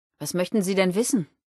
Wastelanders: Audiodialoge